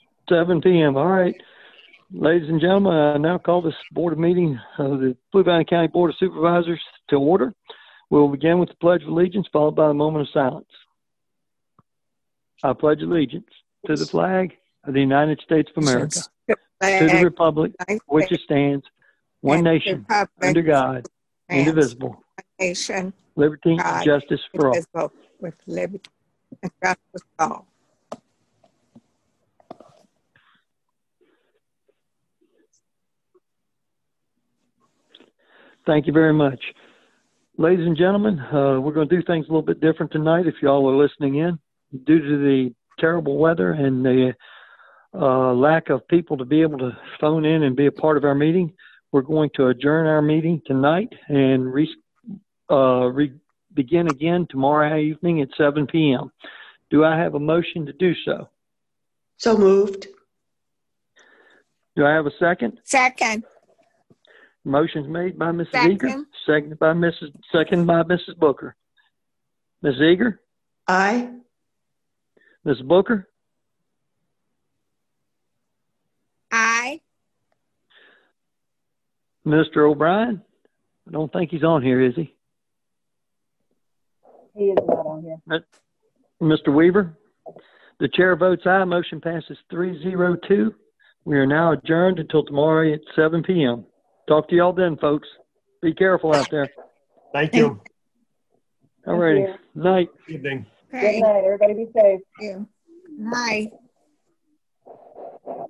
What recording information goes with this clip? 7:00pm - Regular Meeting - Because of winter weather and conditions, the Board of Supervisors adjourned the Dec 16, 2020 meeting until 7:00pm, Thursday, Dec 17, 2020.